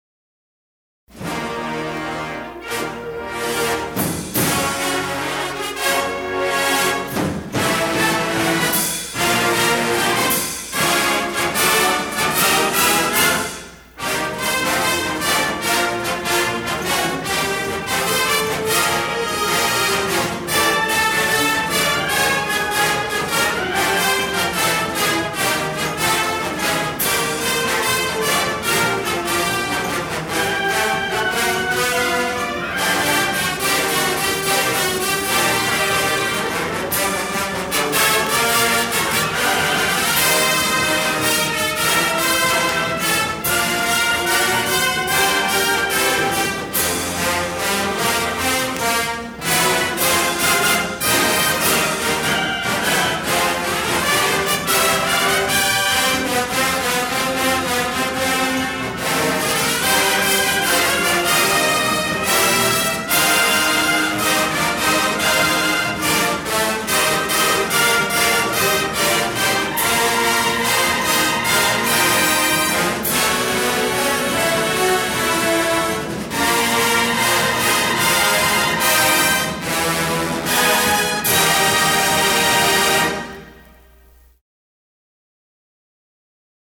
recording session 1974